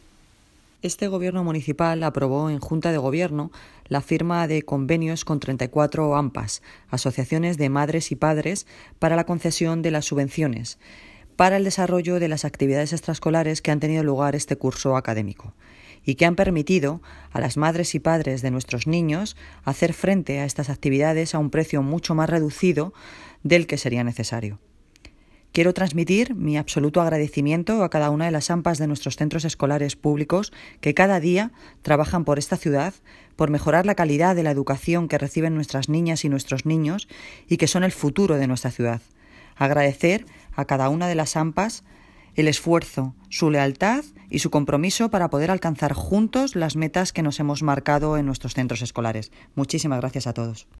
Audio - Noelia Posse (Alcaldesa de Móstoles) Sobre Convenios